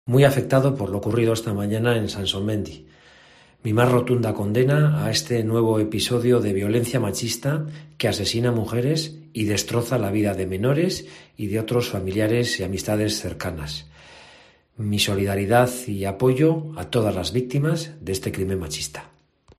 Gorka Urtaran, alcalde de Vitoria-Gasteiz